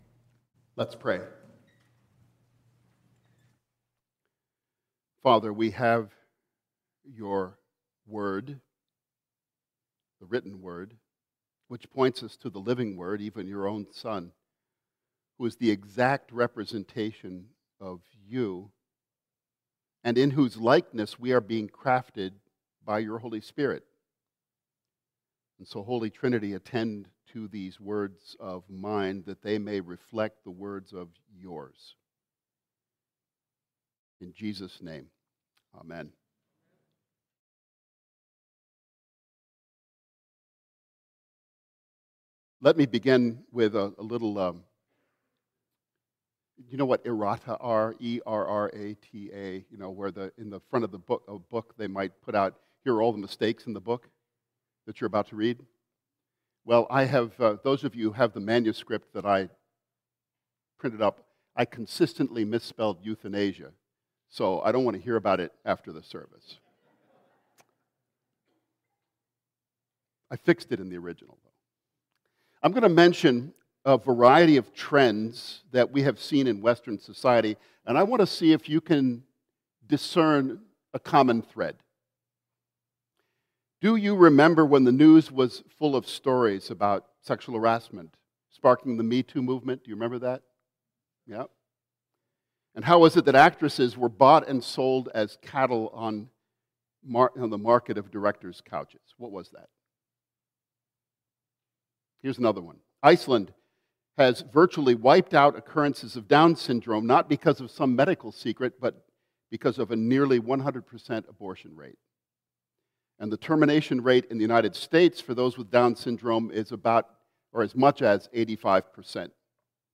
Sermons | Anglicans For Life